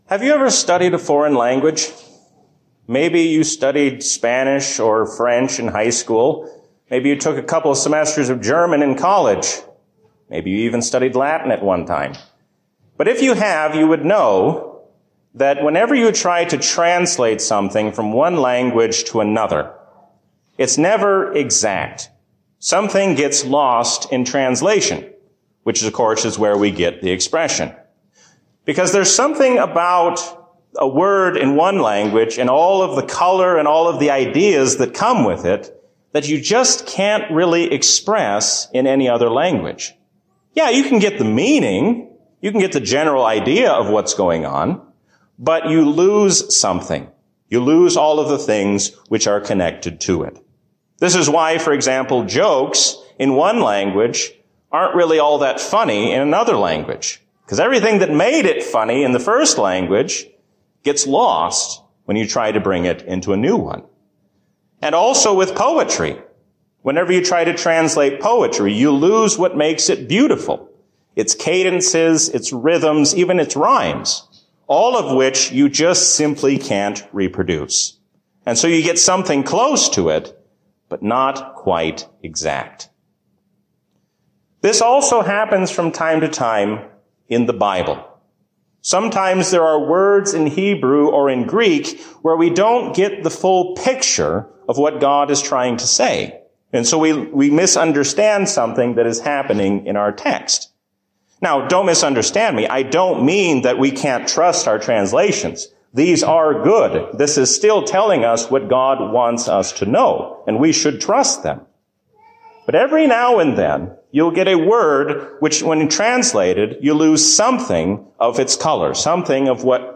A sermon from the season "Trinity 2021." God will provide all you need and more, because there are always leftovers of His mercy.